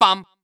New Air Rally Sounds
baBumBumBum_Close3.wav